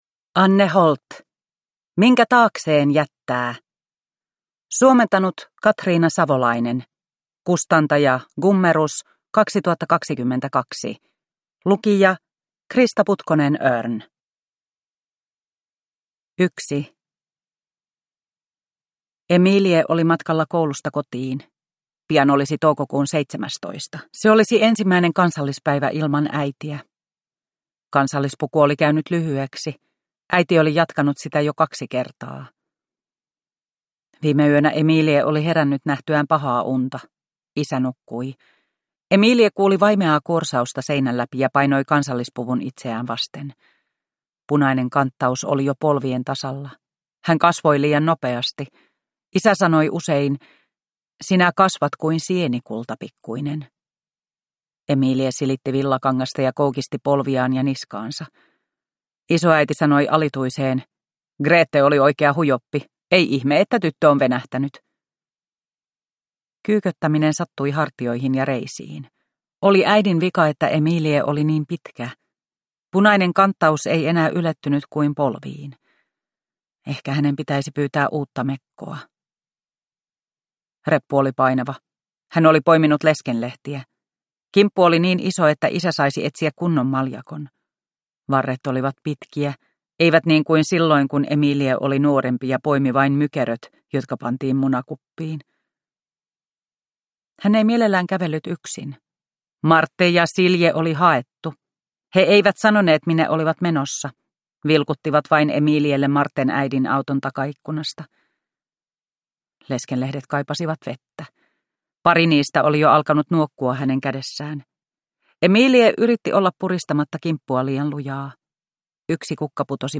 Minkä taakseen jättää – Ljudbok – Laddas ner